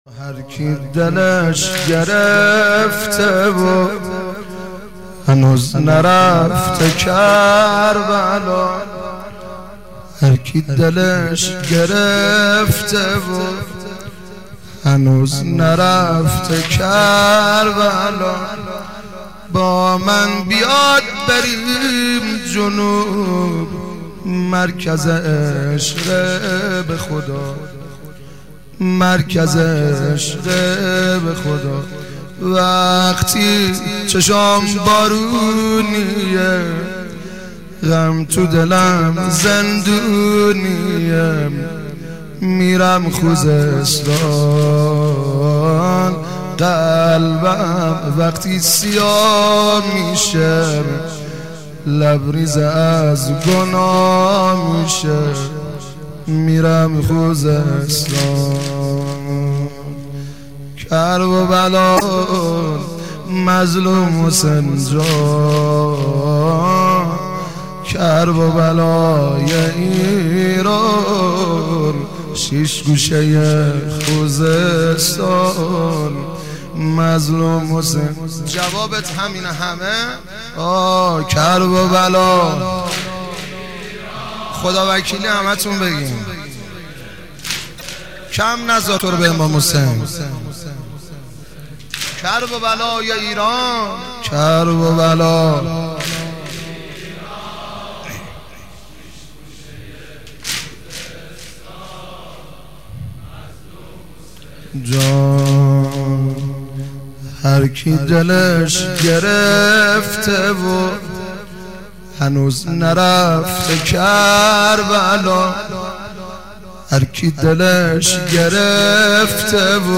صوت حماسی